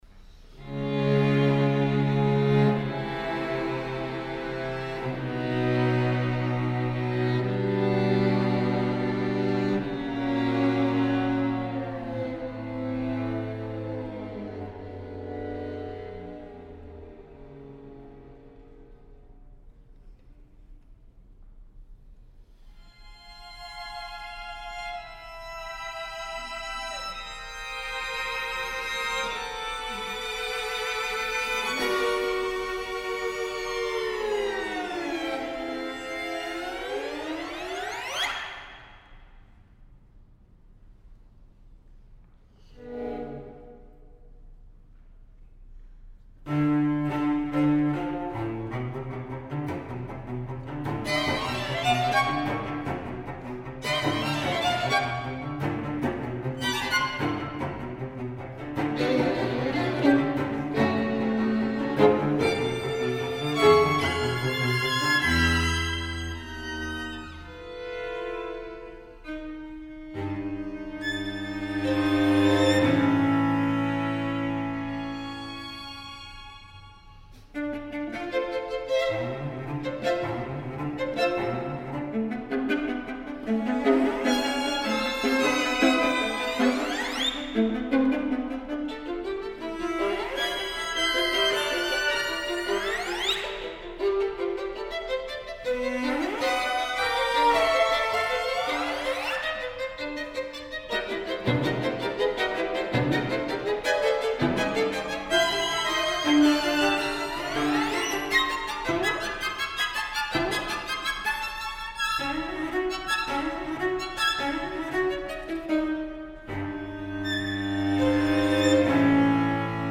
a fantasia
in concert